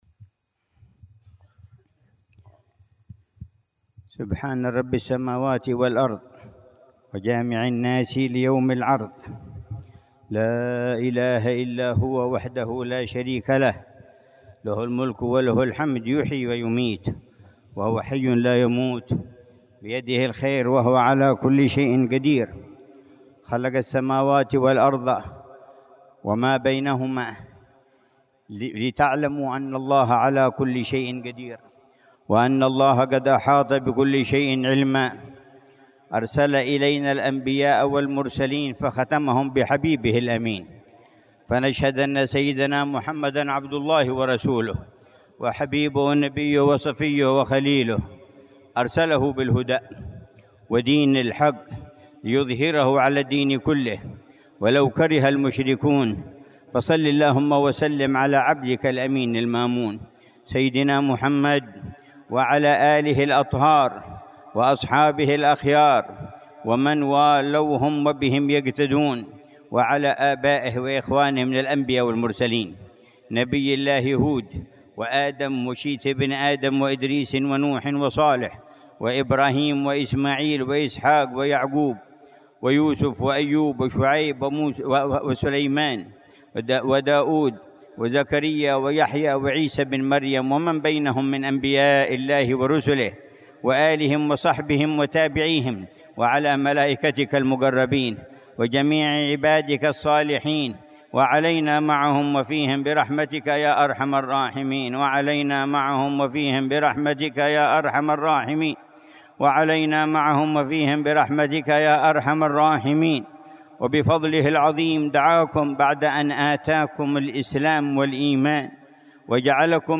مذاكرة العلامة الحبيب عمر بن محمد بن حفيظ في مجلس الوعظ والتذكير في زيارة الإمام حامد بن عمر بن حامد با علوي للنبي هود عليه السلام، شرق وادي حضرموت، عصر السبت 9 شعبان 1446هـ بعنوان: